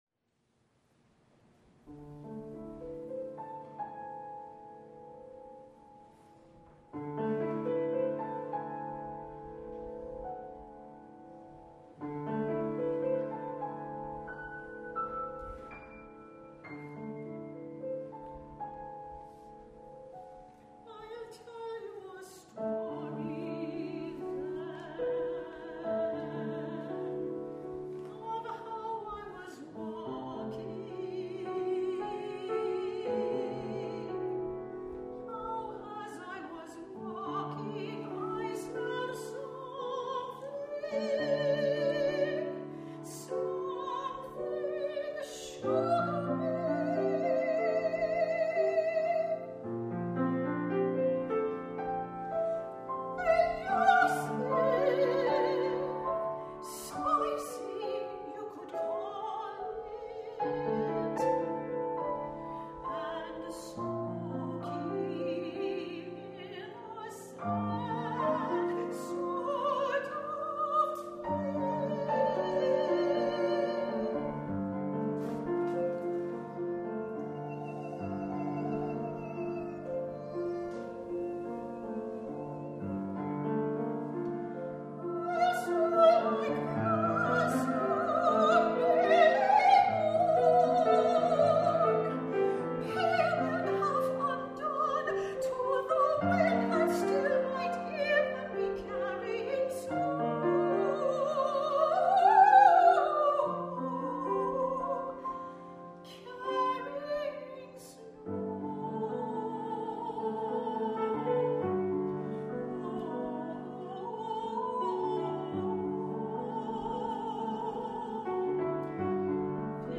High voice, piano